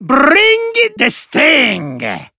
Vo_swarm_catchphrase_01.ogg